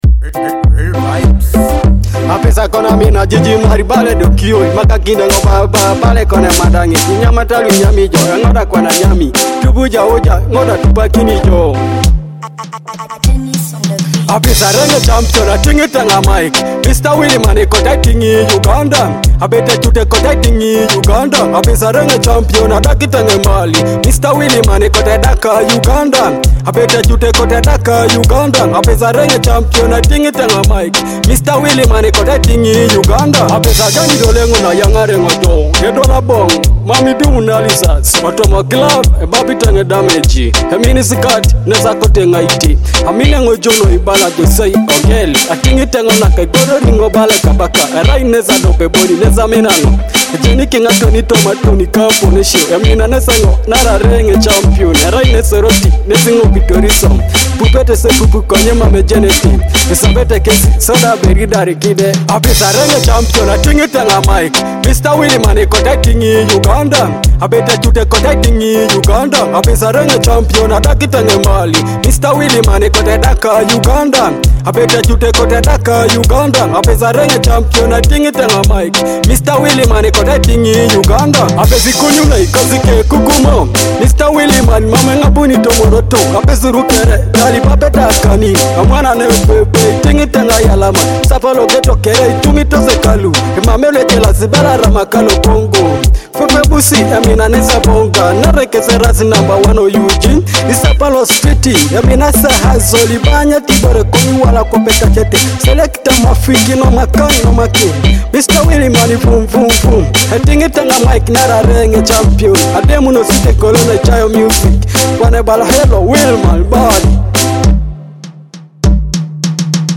energetic